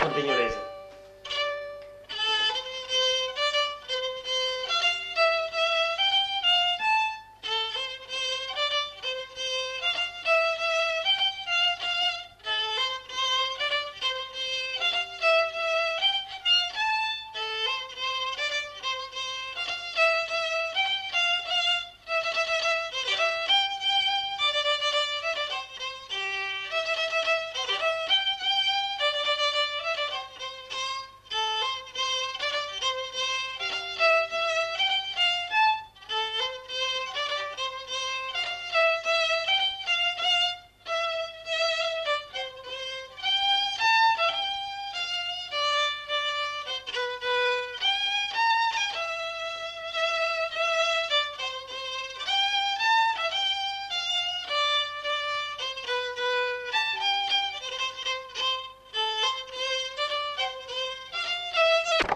Genre : morceau instrumental
Instrument de musique : violon
Danse : polka
Ecouter-voir : archives sonores en ligne